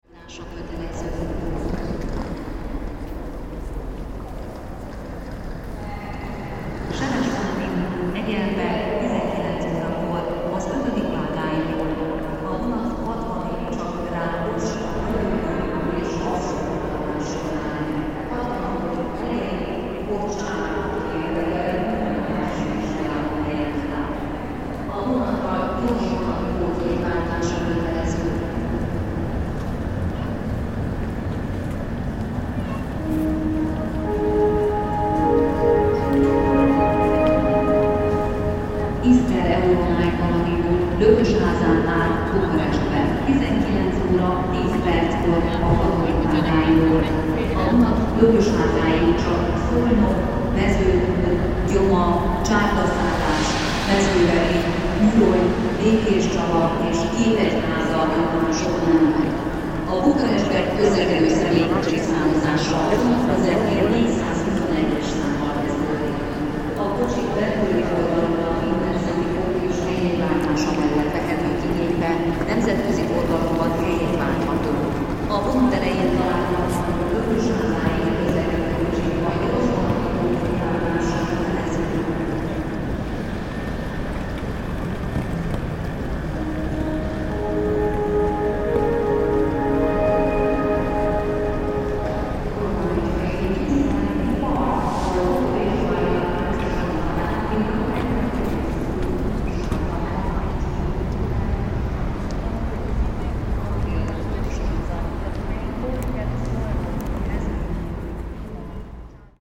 Keleti train station, Budapest